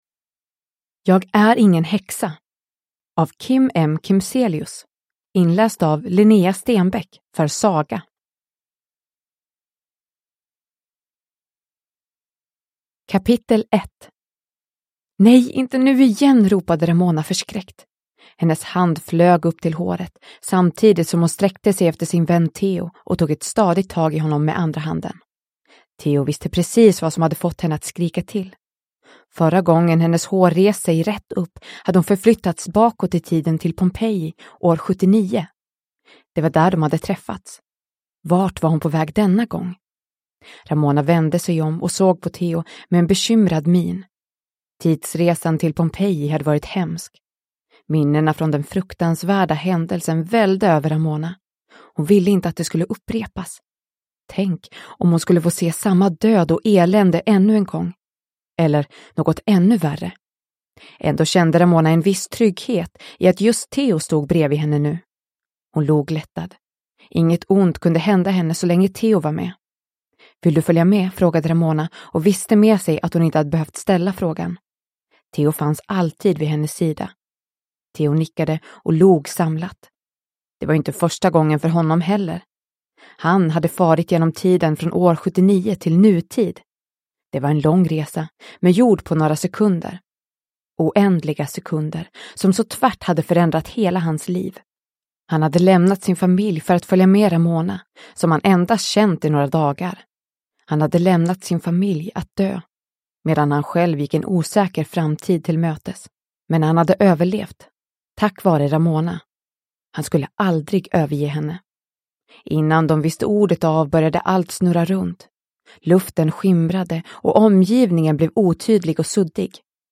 Jag är ingen häxa – Ljudbok – Laddas ner